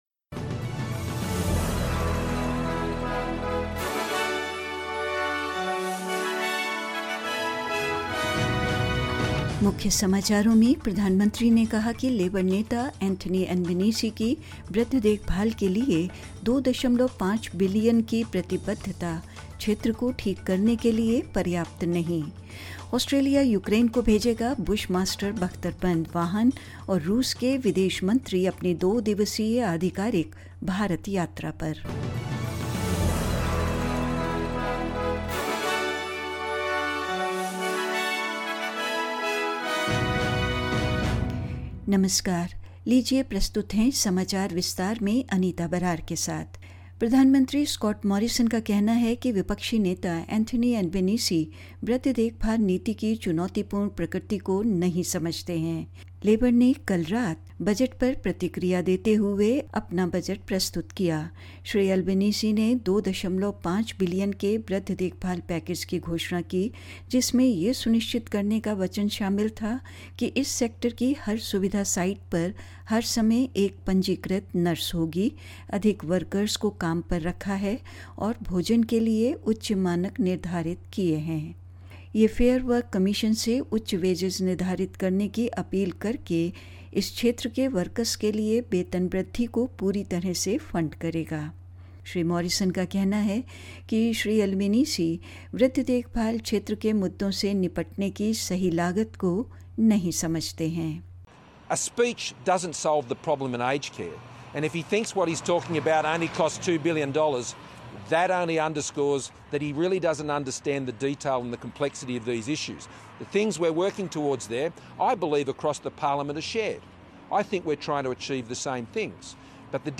In this latest SBS Hindi bulletin: The Prime Minister says Labor leader Anthony Albanese's $2.5 billion pledge to aged care won't be enough to fix the sector; Australia to send Bushmaster armoured vehicles to Ukraine; Russia's Foreign Minister Sergei Lavrov is on a two-day official visit to India and more news.